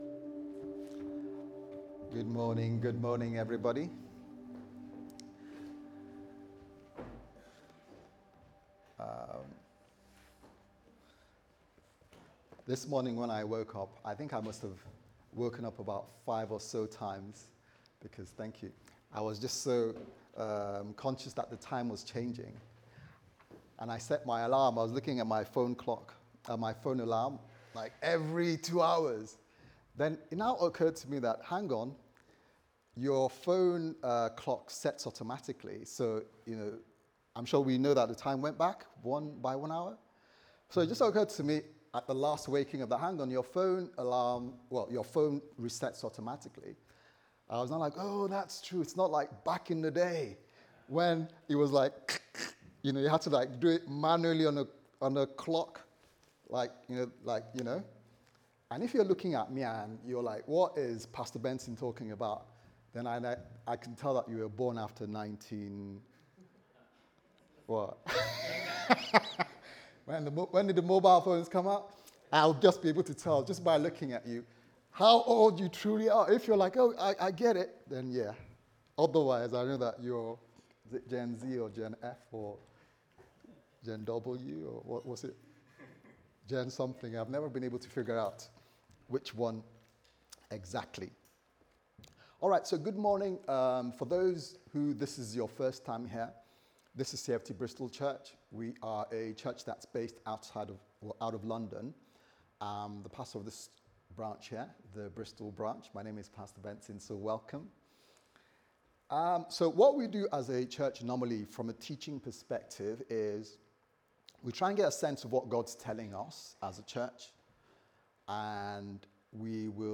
What Really Matters Service Type: Sunday Service Sermon « What Really Matters In This Life ?